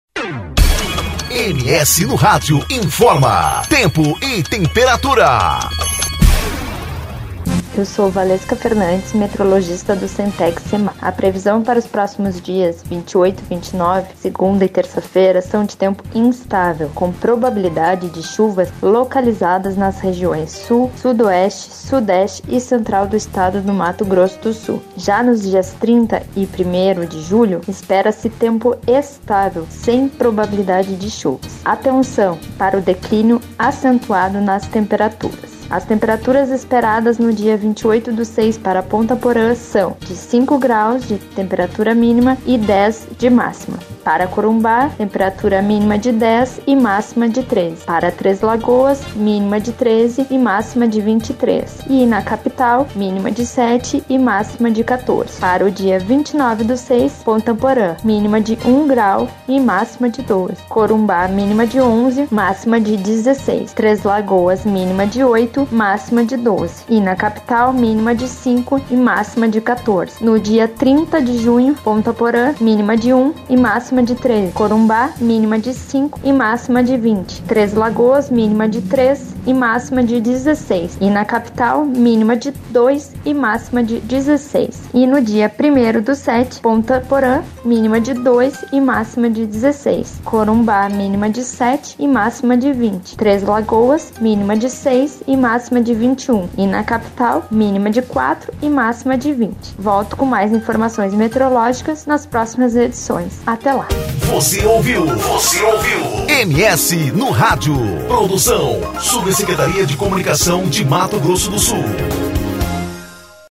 Previsão do Tempo: Semana de tempo instável e baixas temperaturas